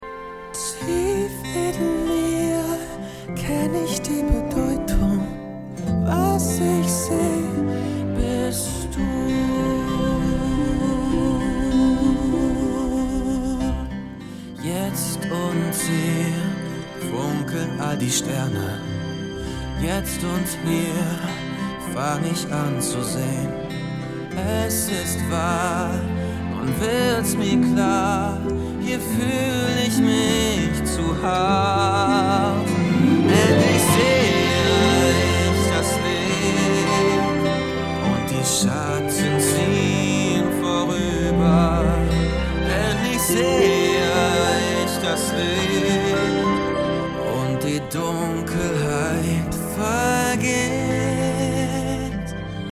sehr variabel, hell, fein, zart
Jung (18-30)
Vocals (Gesang)